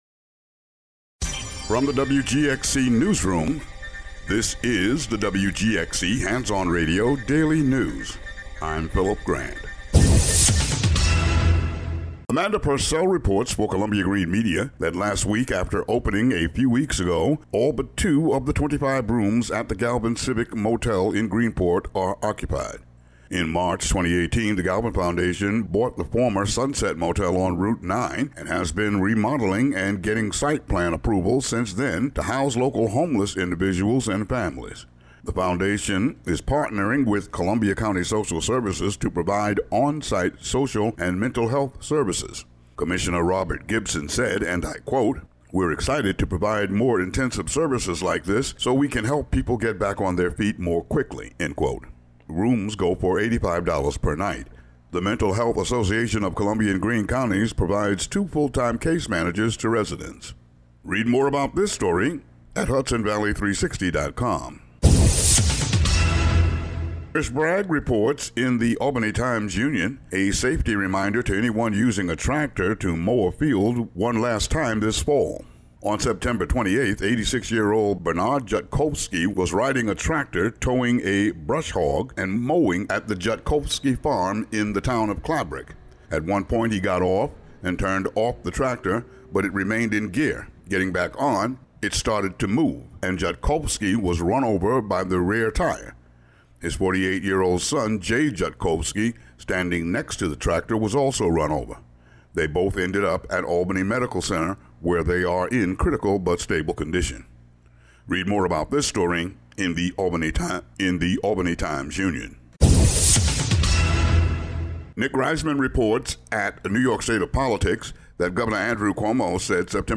The audio version of the local news for Mon., Sept. 30.